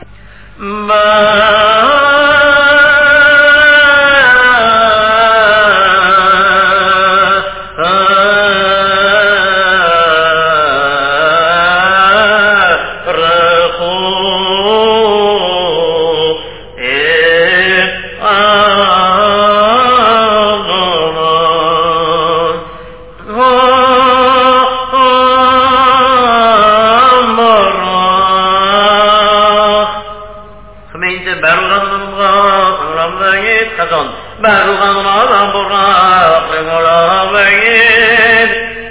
Chazzan
Congregation